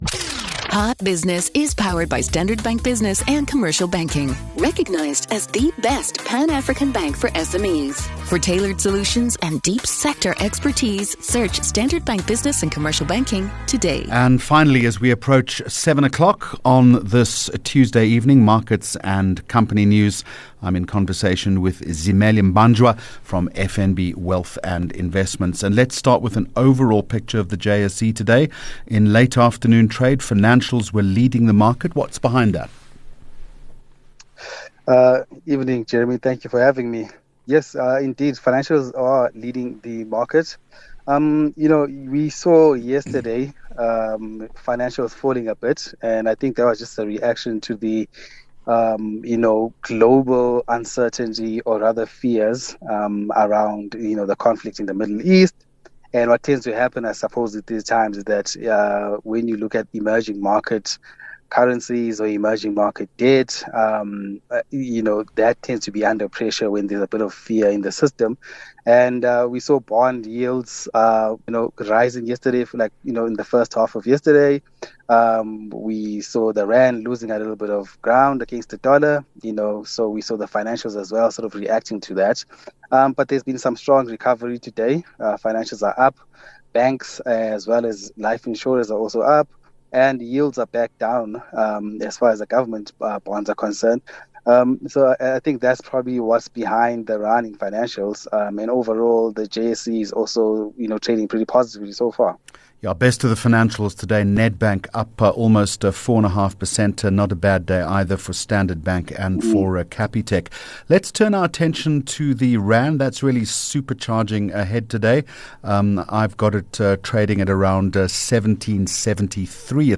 24 Jun Hot Business Interview